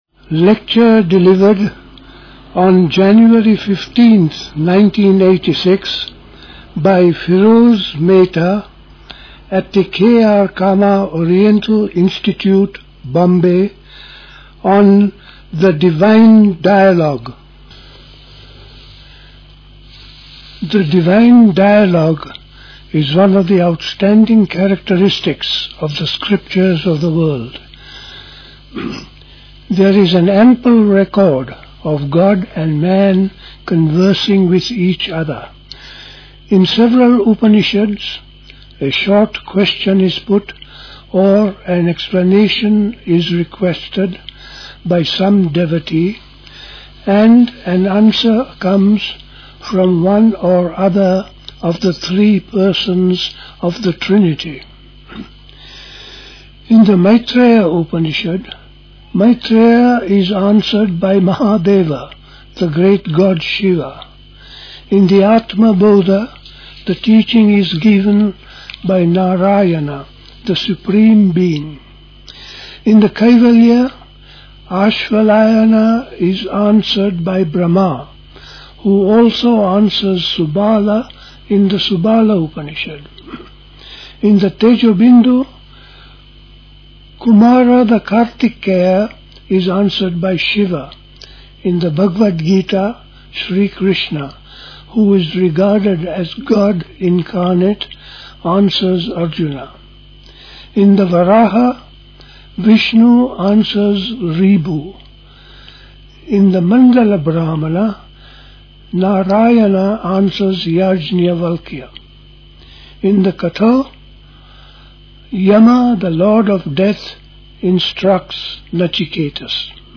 Recorded in Bombay.